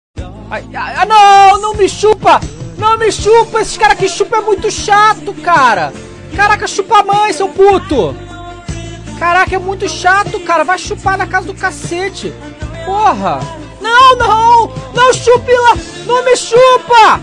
Category: Meme Soundboard